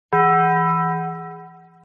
Taco Bell Bong Bruh Sound Button: Unblocked Meme Soundboard
Taco Bell Bong Bruh Sound Effects